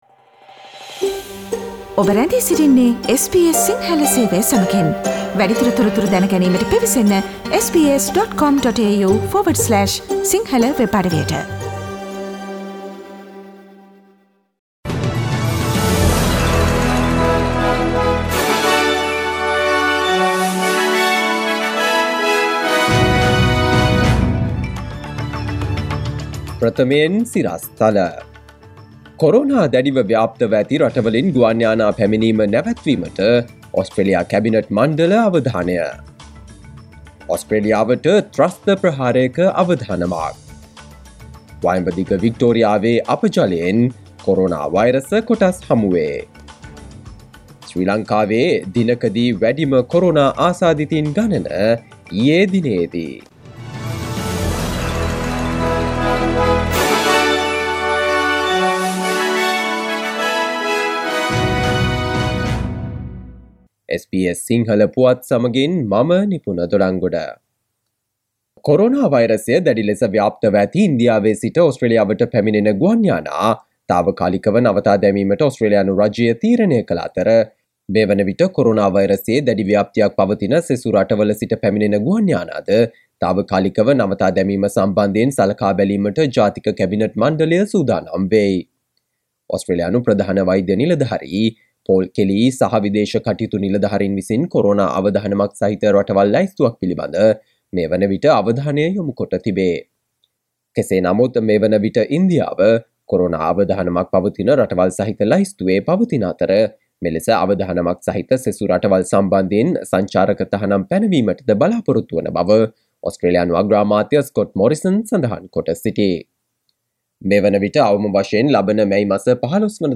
Here are the most prominent Australian and Sri Lankan news highlights from SBS Sinhala radio daily news bulletin on Friday 30 April 2021.